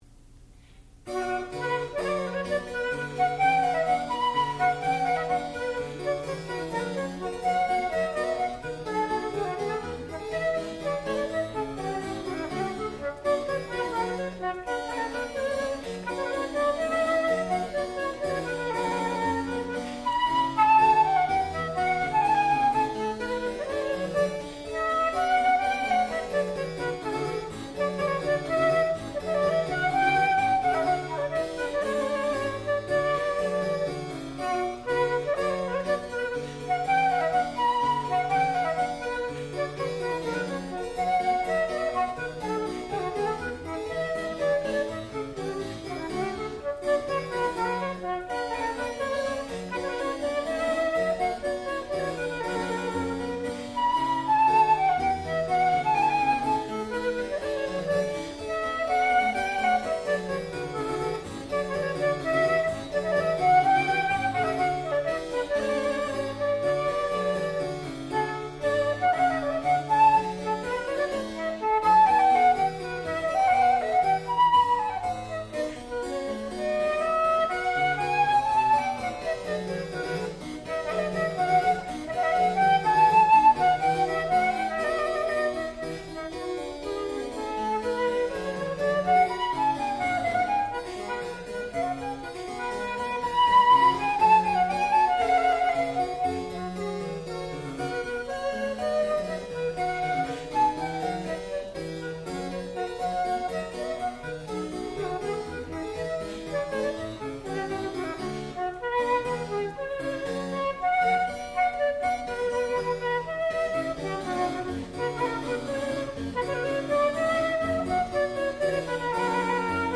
sound recording-musical
classical music
harpsichord
flute
Junior Recital